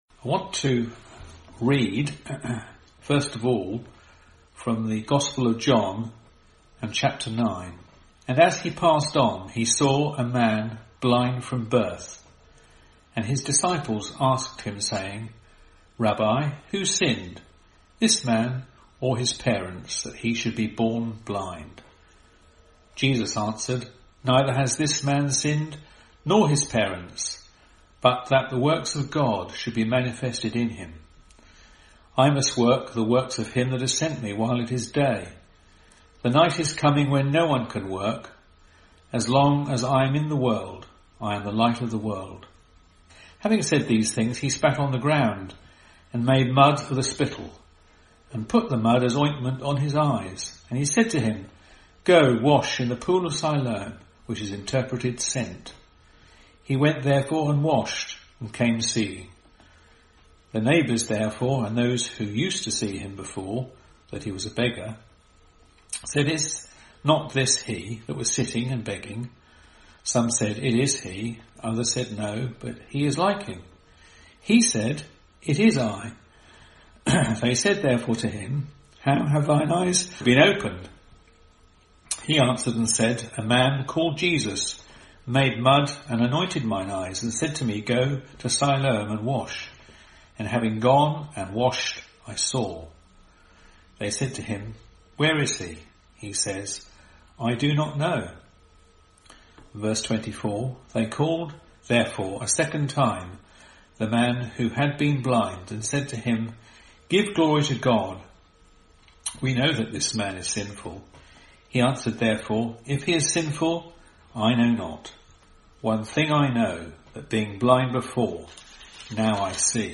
In this Gospel preaching, you will hear of two men who were healed after they had an encounter with the Lord Jesus Christ.